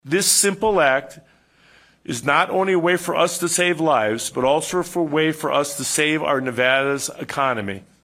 Governor Steve Sisolak announced Wednesday that face coverings will be mandatory for all people in all public places beginning Friday. During a news conference yesterday, the governor said public places include restaurants, grocery stores, public transportation and other businesses.